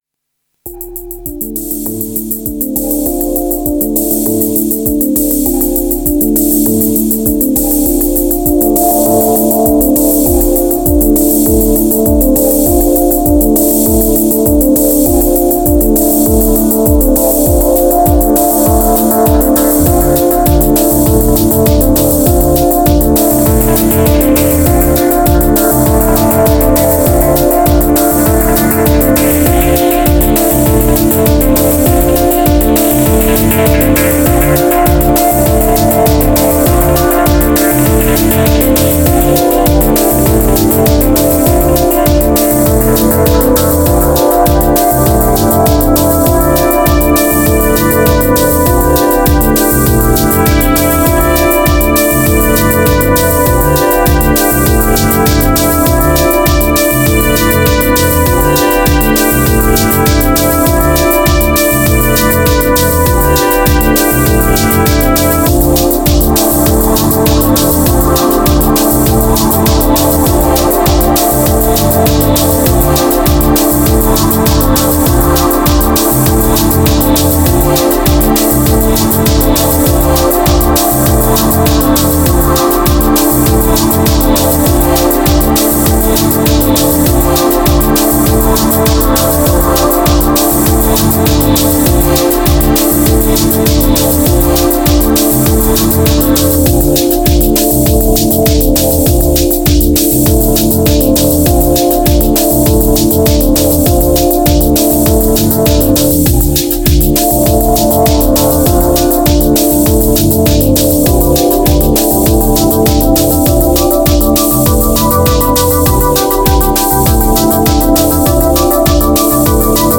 1121📈 - 96%🤔 - 100BPM🔊 - 2025-08-08📅 - 1076🌟
Heat Kicks Moon Modal Empire Groove Bass Attic Unity